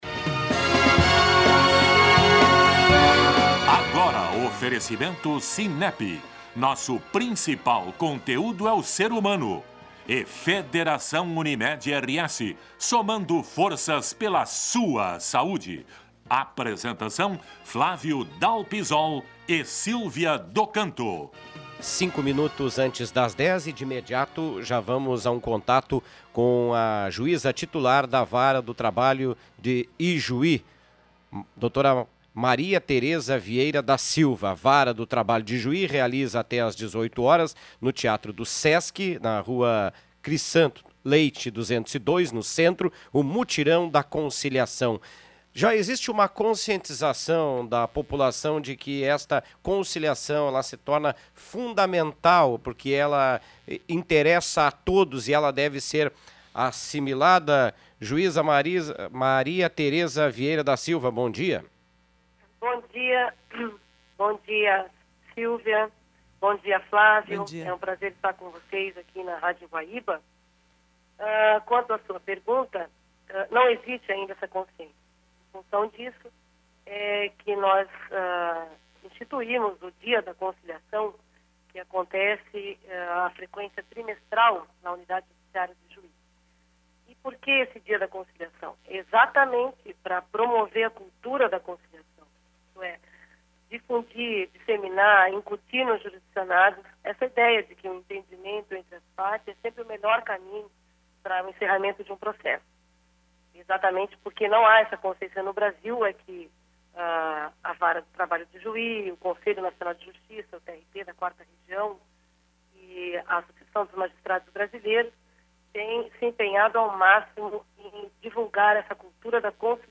Rádio Guaíba: Entrevista Juíza Maria Teresa Vieira da Silva
Clique no ícone de áudio à direita do título para ouvir entrevista (4min51s) da Juíza Maria Teresa Vieira da Silva, Titular da Vara do Trabalho de Ijuí, ao programa "Agora", da Rádio Guaíba.